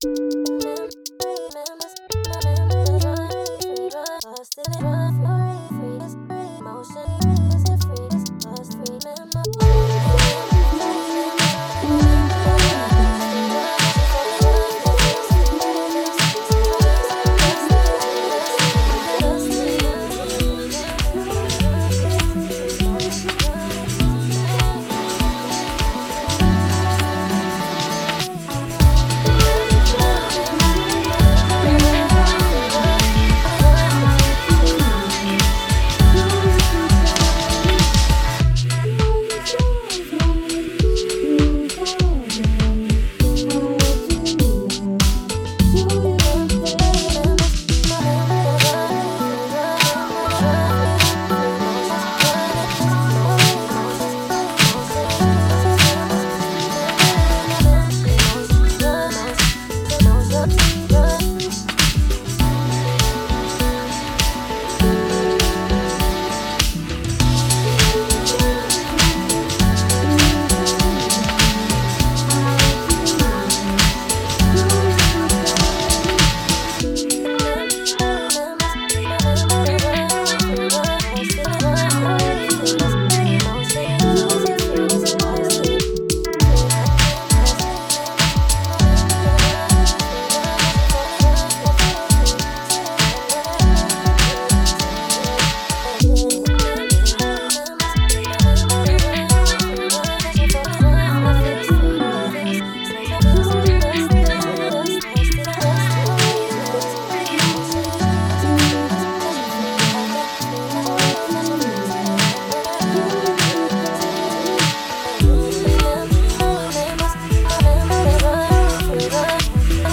Glitched layered vocals with variety of drums and bass synthesizers, and guitar that comes in.